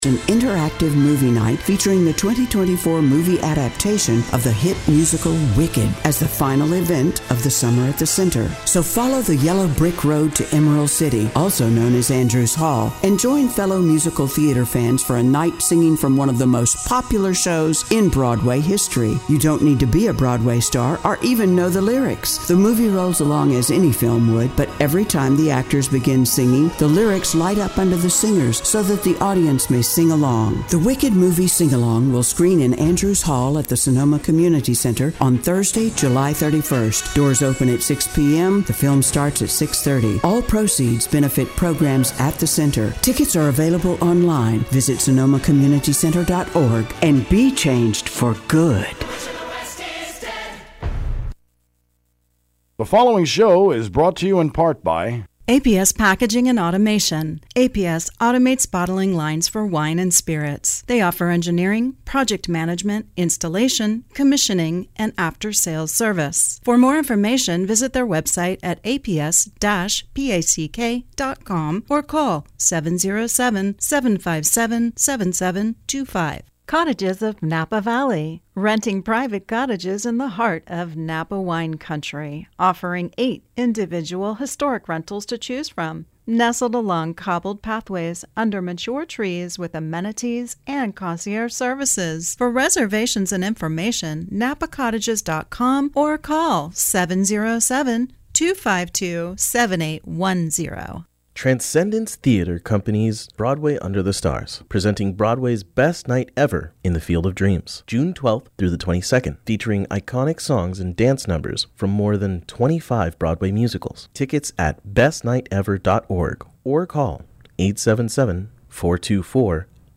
KSVY (91.3 FM)’s Interview with Chef and Author Douglas Keane